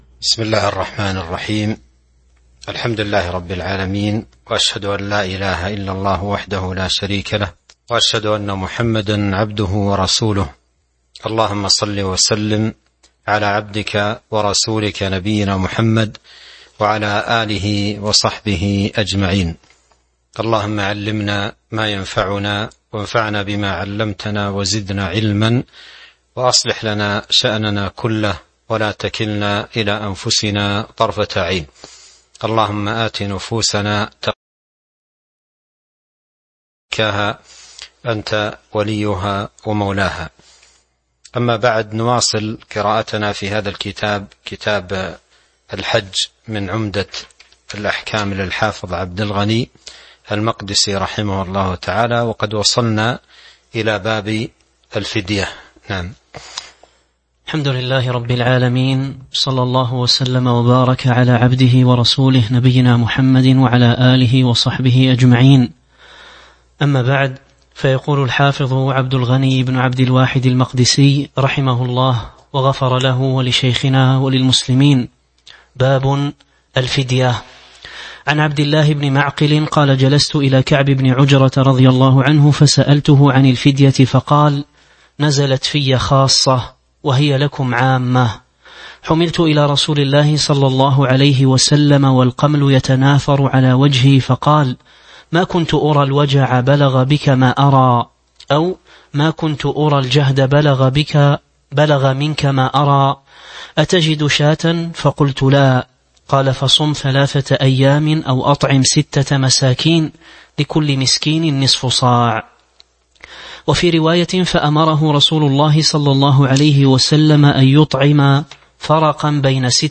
تاريخ النشر ٢٥ ذو القعدة ١٤٤٢ هـ المكان: المسجد النبوي الشيخ